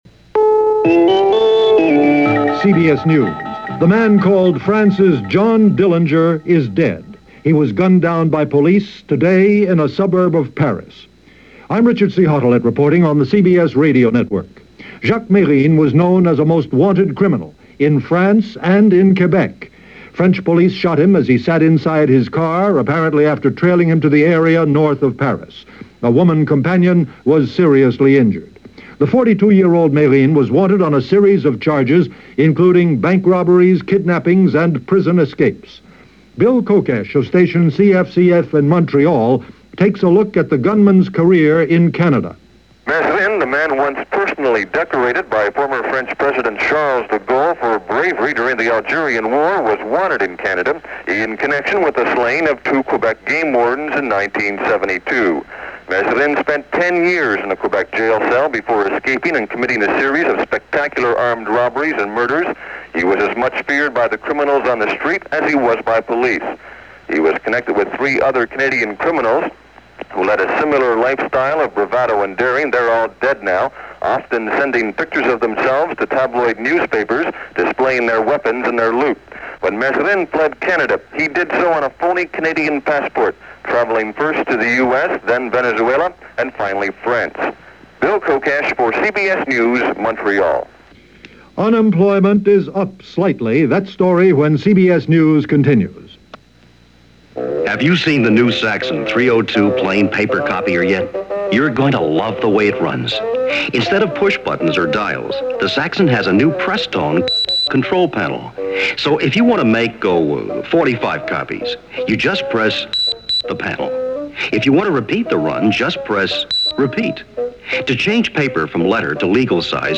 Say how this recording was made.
And that’s a small slice of what went on, this 2nd day of November in 1979, as presented by CBS Radio’s Hourly News.